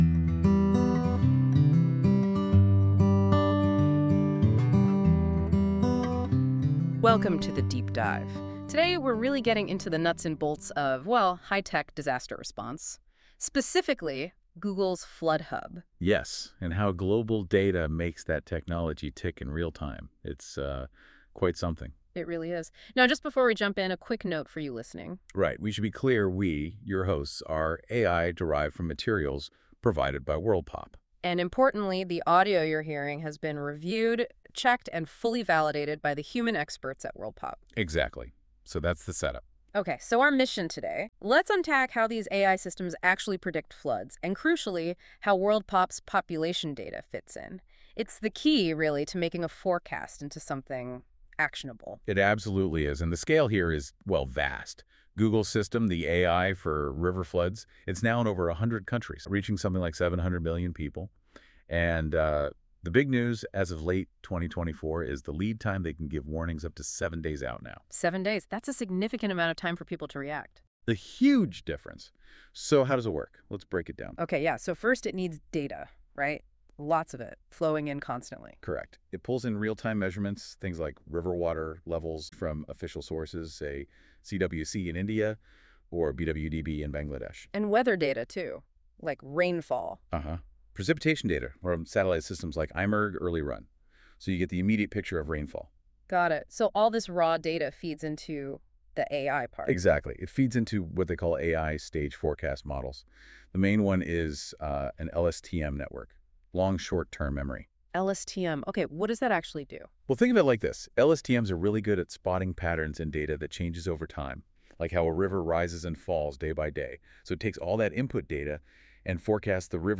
This feature uses AI to create a podcast-like audio conversation between two AI-derived hosts that summarise key points of documents - in this case the Google Environment Report 2025 plus the two relevant journal articles listed below.
Music: My Guitar, Lowtone Music, Free Music Archive (CC BY-NC-ND)